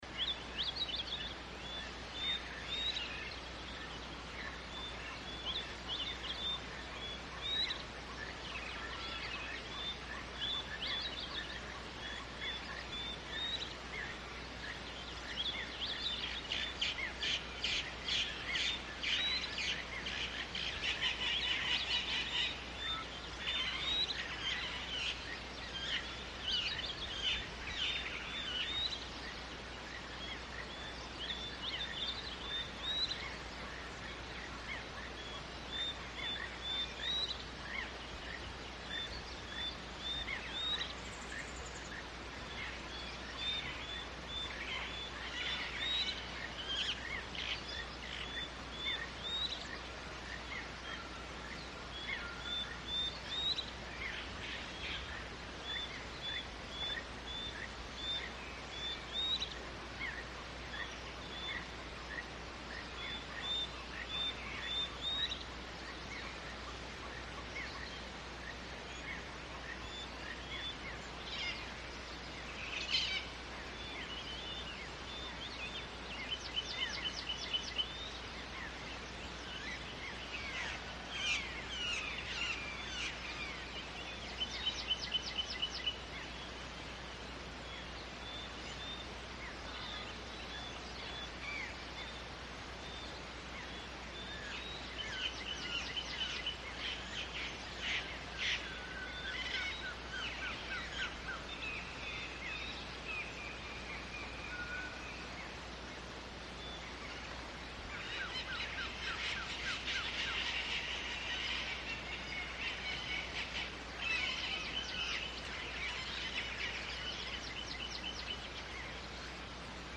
Guatemalan jungle at dawn from top of La Danta pyramid ( El Mirador )